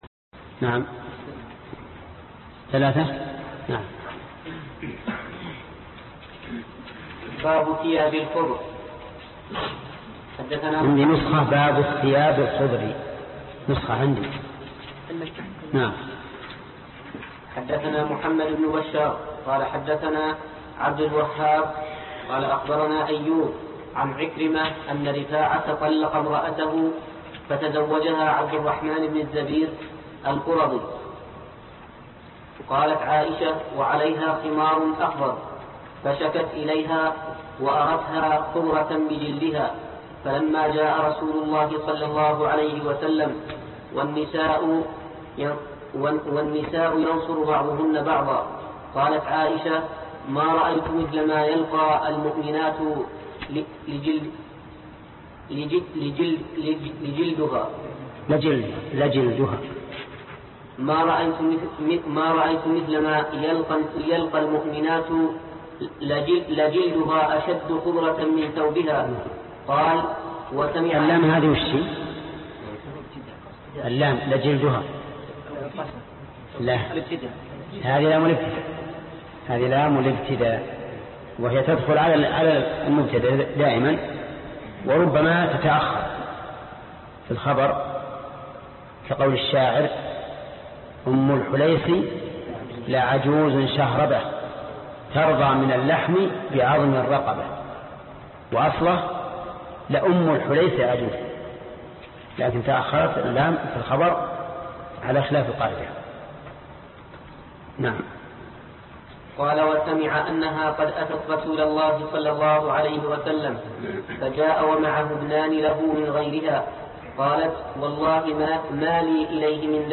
الدرس السادس عشر-شرح كتاب اللباس من صحيح البخاري - فضيلة الشيخ محمد بن صالح العثيمين رحمه الله